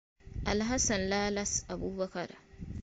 pronunciation (born 25 December 1994) is a Ghanaian professional footballer who plays as a centre back for Major League Soccer club FC Dallas.